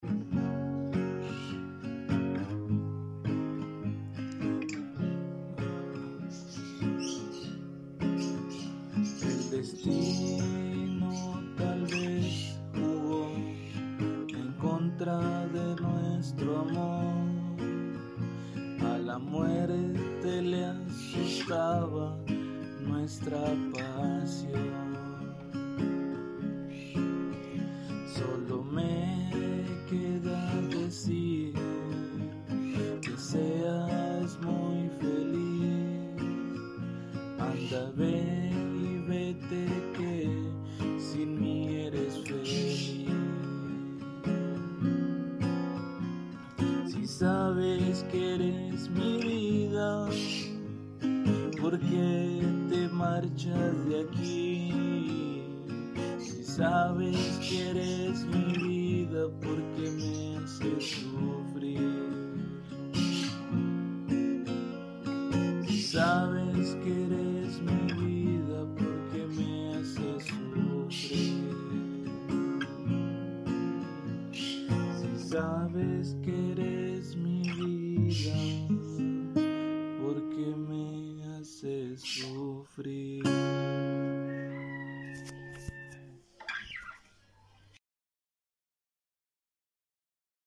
me disculpan los sonidos de fondo